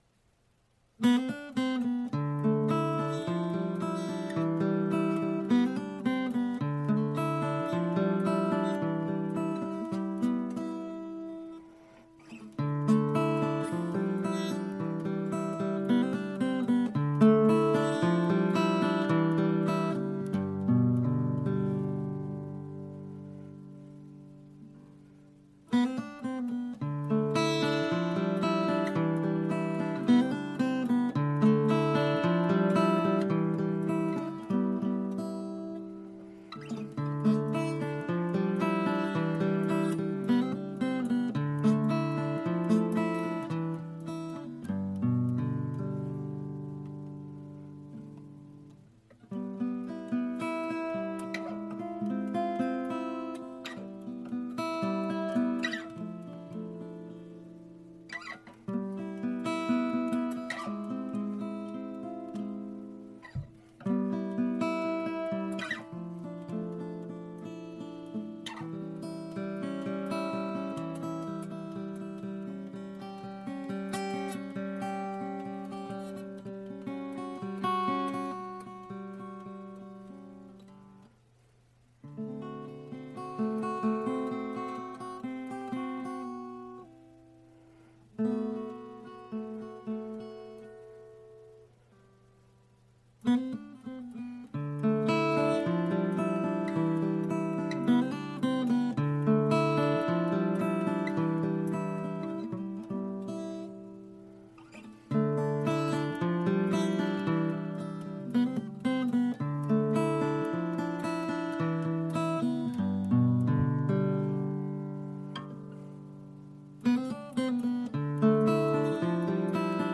a still morning song of the heart